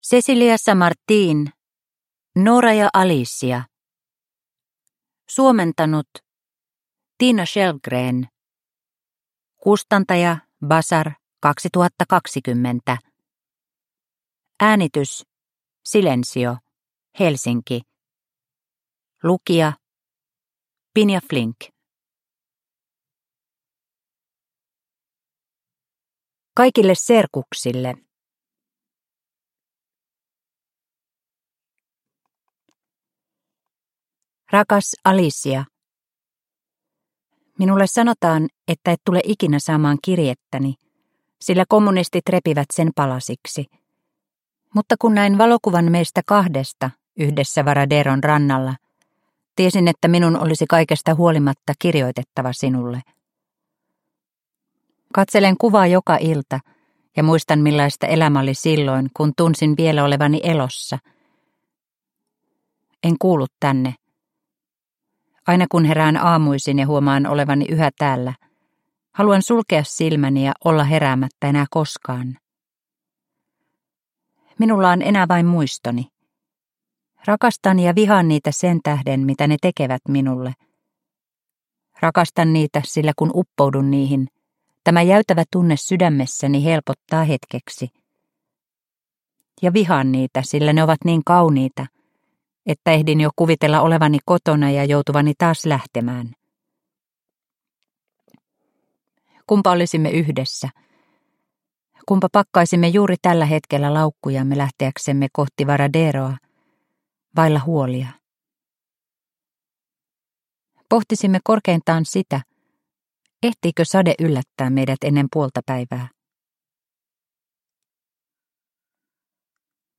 Nora & Alicia – Ljudbok – Laddas ner